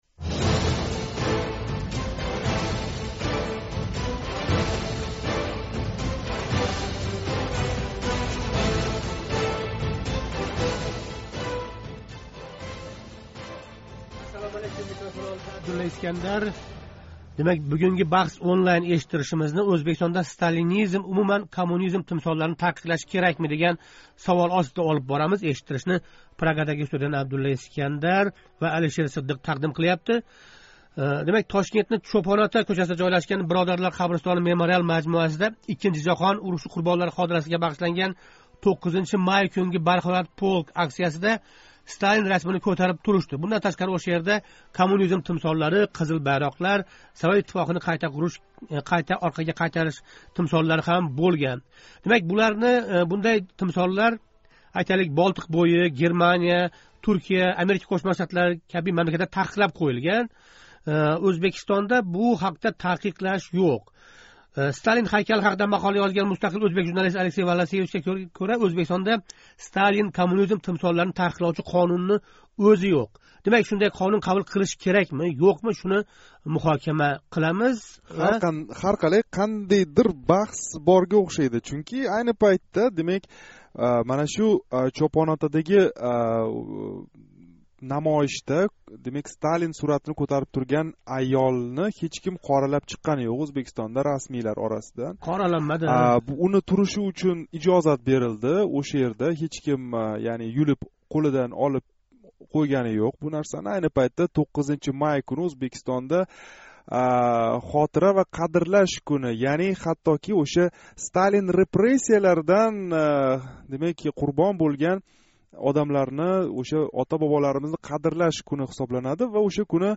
Тадбирда кузатилган совет тузумини қўмсаш ва репрессия тимсоли бўлган Сталин шахсига сиғиниш аломатларини ўзбеклар зеҳниятидан қандай йўқотиш мумкин? Бугунги жонли суҳбатда шу ҳақда гаплашамиз.